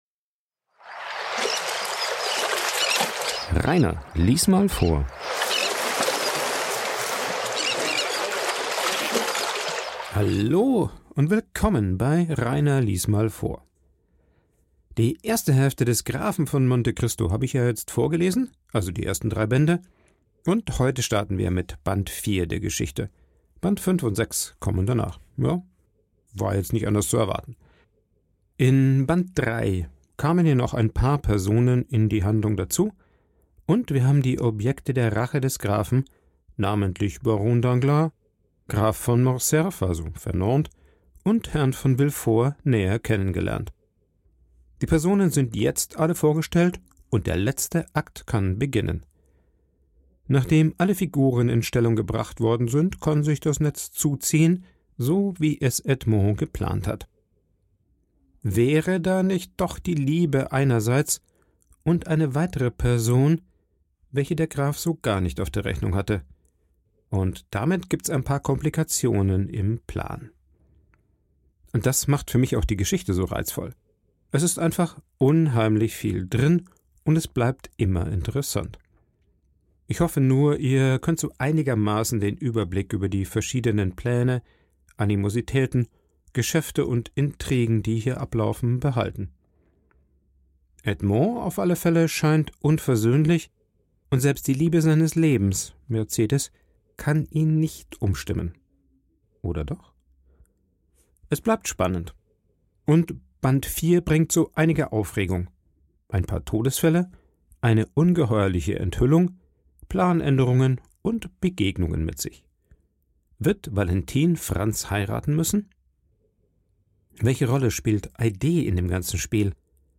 Der Vorlese Podcast
Ein Vorlese Podcast